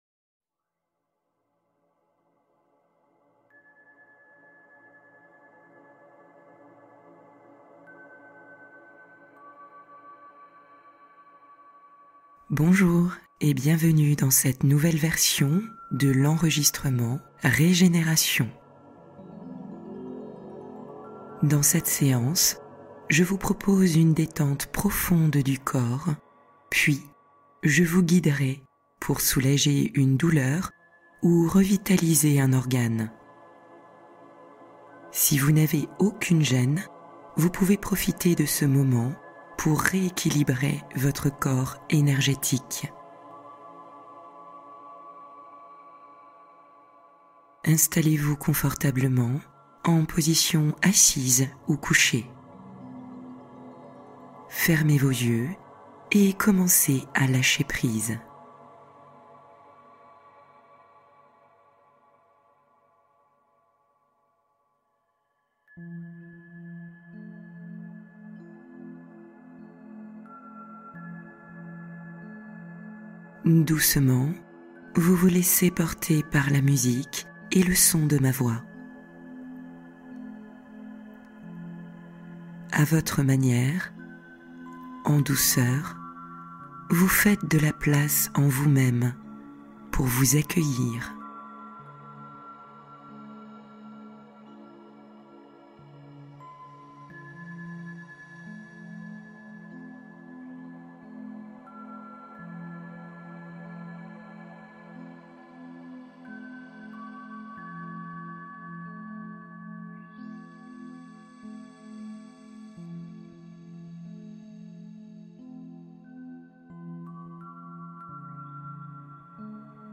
Méditation matinale express : vitalité, élan et pensées positives